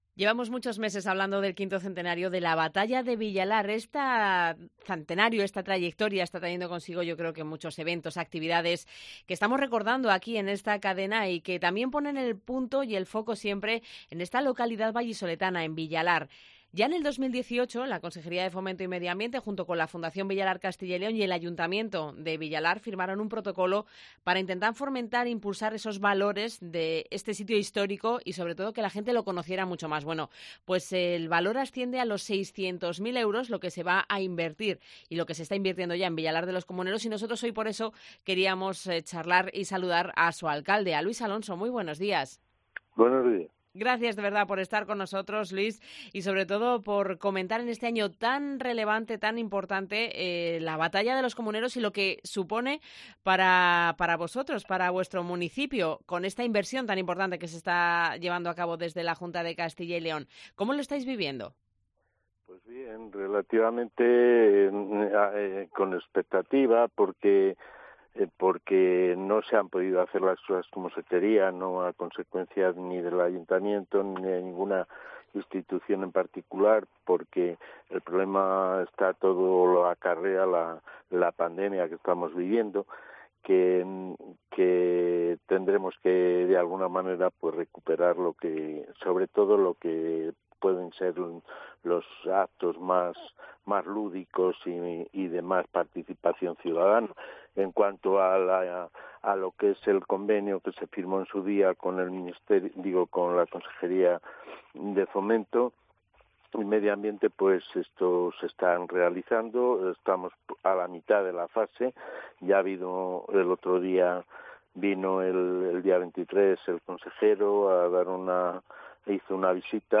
Las instituciones se vuelcan con la localidad vallisoletana de Villalar de los Comuneros, escenario de la revuelta. Hablamos con el alcalde, Luis Alonso Laguna, quien confía en recuperar algunos de los actos que la pandemia no permitió celebrar.